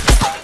破碎音效.wav